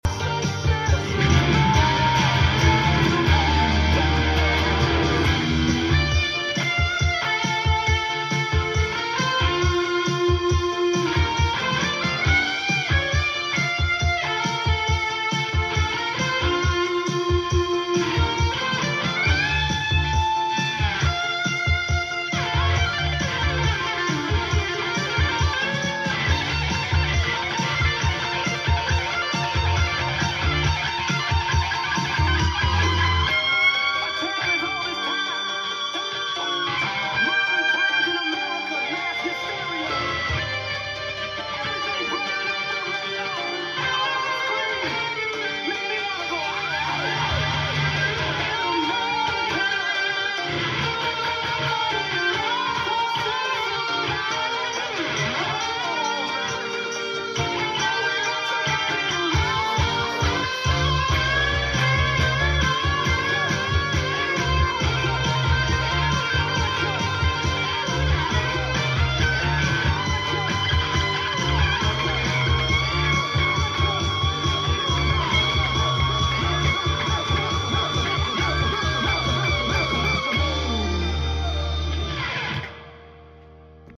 Synth Solo